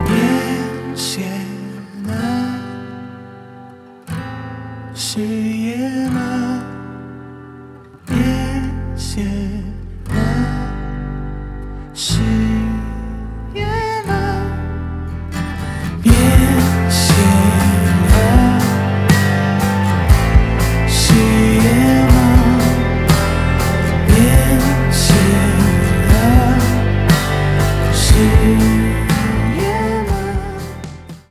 • Качество: 320, Stereo
спокойные
романтичные
казахские